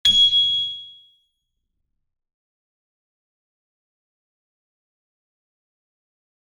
piano-sounds-dev
HardAndToughPiano